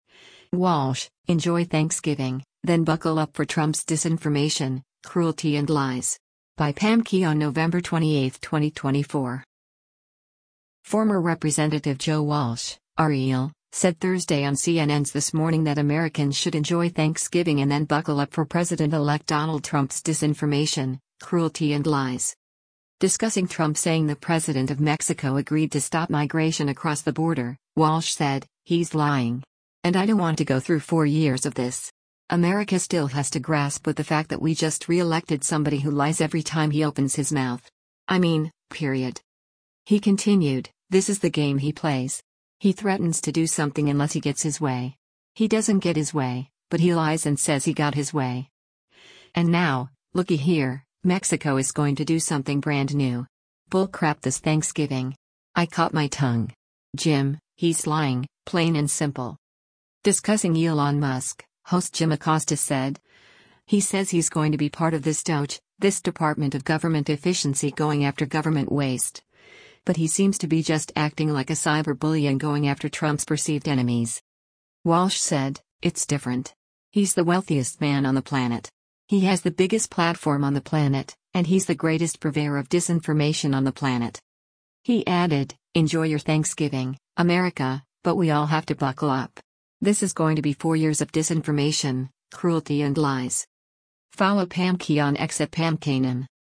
Former Rep. Joe Walsh (R-IL) said Thursday on CNN’s “This Morning” that Americans should enjoy Thanksgiving and then “buckle up” for President-elect Donald Trump’s “disinformation, cruelty and lies.”
Discussing Elon Musk, host Jim Acosta said, “He says he’s going to be part of this DOGE, this Department of Government Efficiency going after government waste, but he seems to be just acting like a cyberbully and going after Trump’s perceived enemies.”